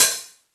Index of /90_sSampleCDs/Classic_Chicago_House/Drum kits/kit03
cch_06_hat_open_high_live_frank.wav